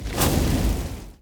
Fireball 3.ogg